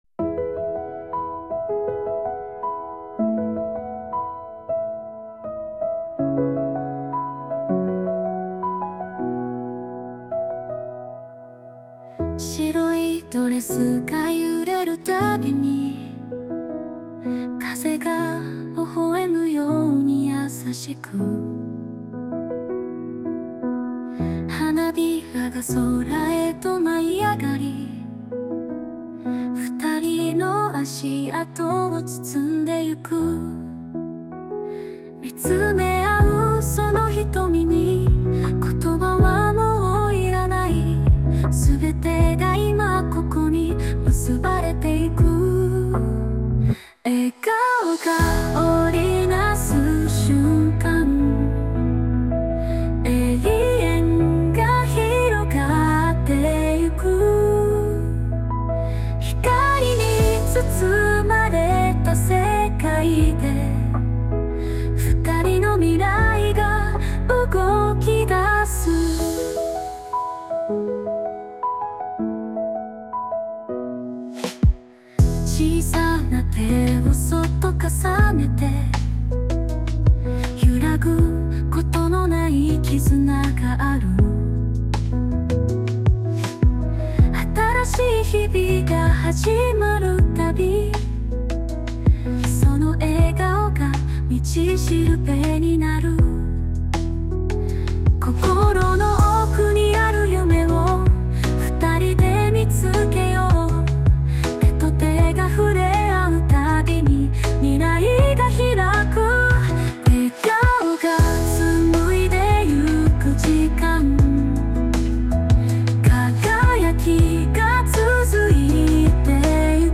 邦楽女性ボーカル著作権フリーBGM ボーカル
女性ボーカル（邦楽・日本語）曲です。